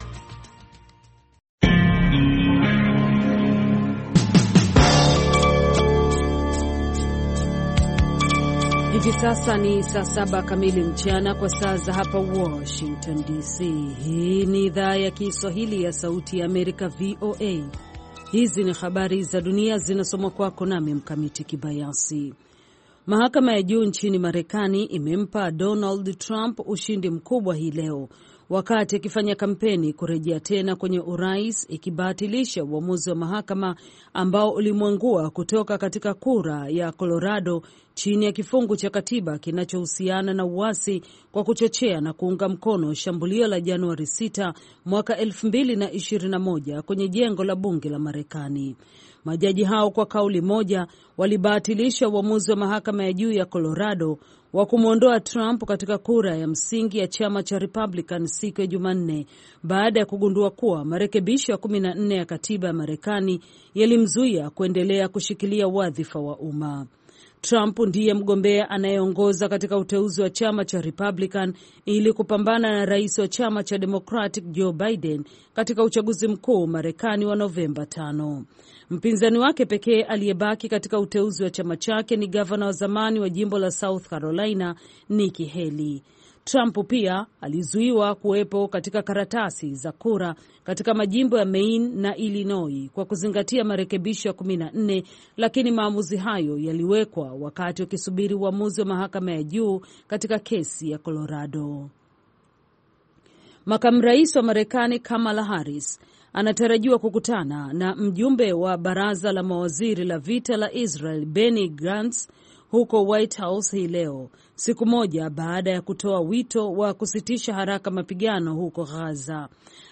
Kwa Undani ni matangazo ya dakika 25 yanayochambua habari kwa undani zaidi na kumpa msikilizaji maelezo ya kina kuliko ilivyo kawaida kuhusu tukio au swala lililojitokeza katika habari.